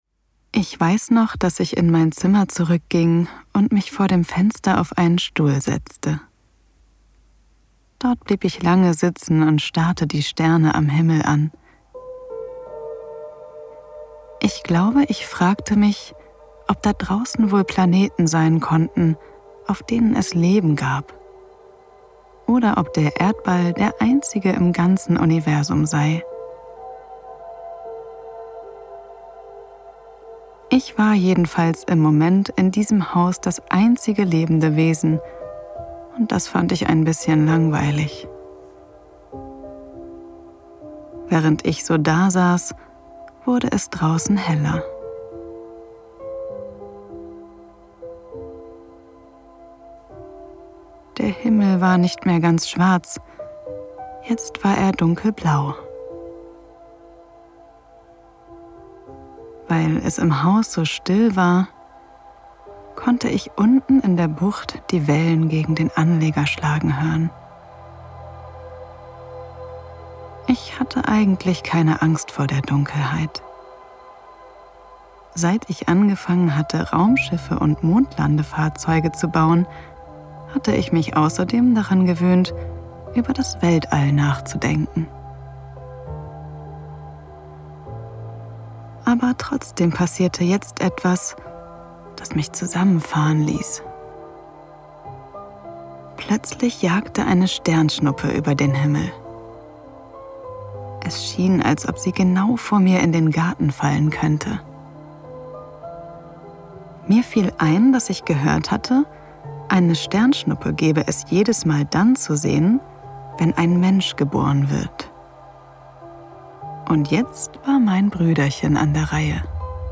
markant
Jung (18-30)
Norddeutsch
Audiobook (Hörbuch)